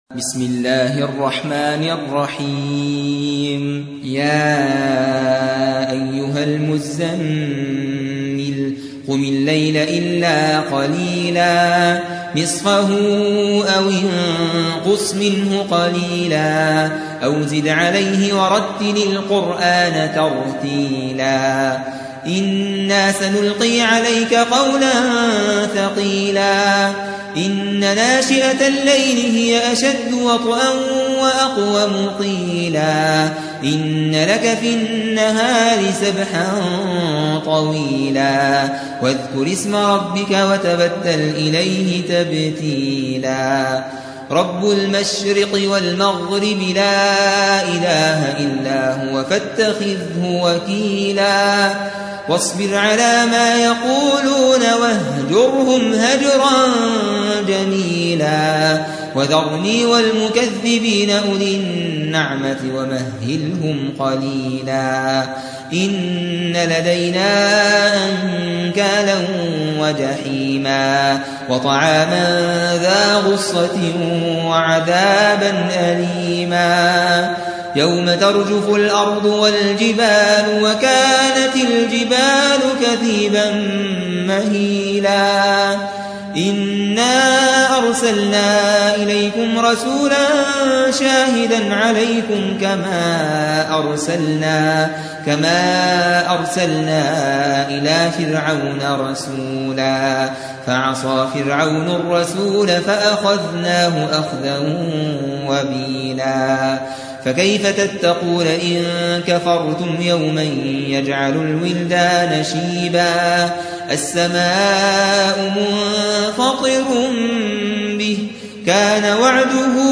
73. سورة المزمل / القارئ